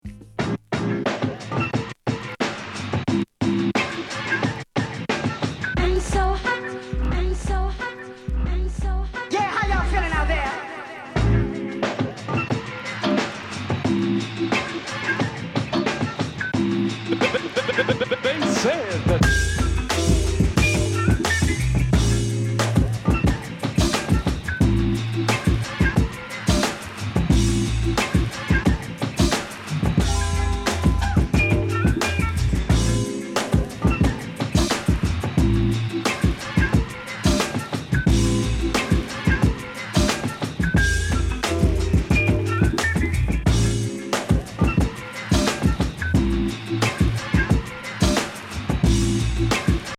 黒人音楽のエッセンスを様々なフォーマットでシンプルに打ち出した